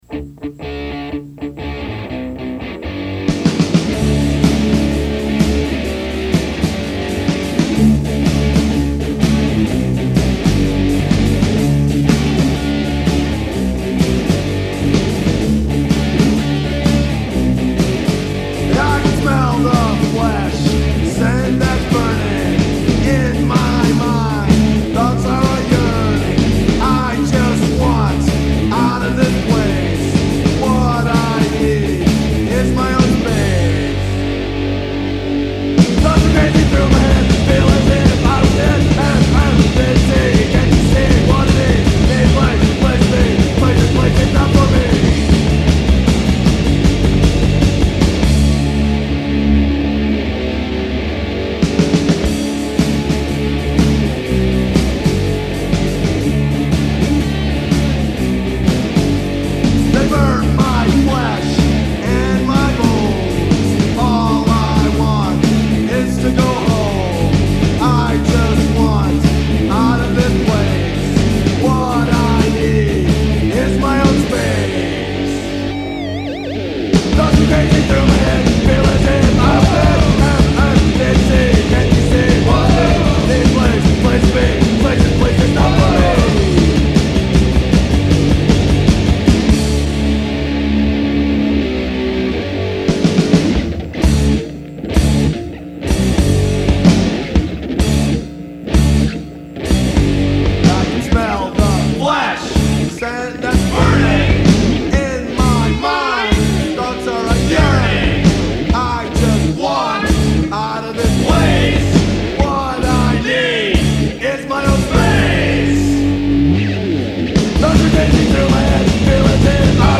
Hardcore , Straight Edge